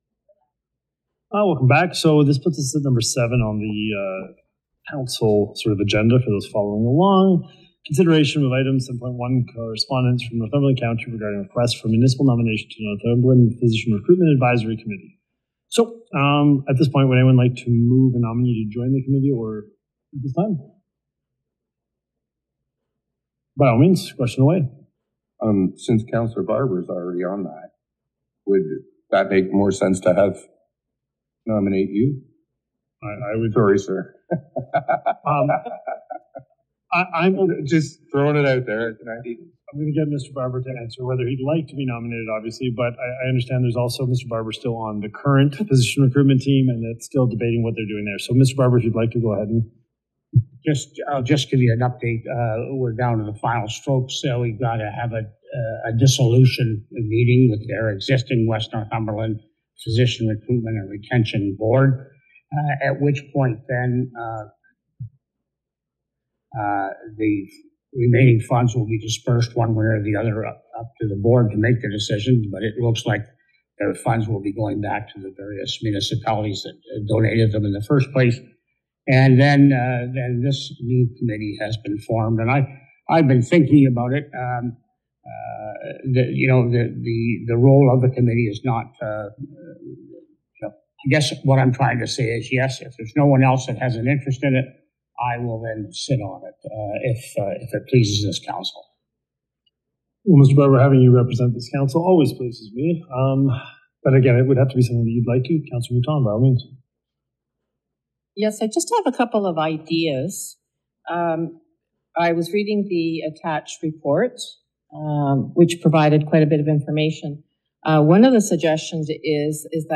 It was out with the old and in with the new for Cobourg Councillor Randy Barber at the special council meeting held Aug. 6.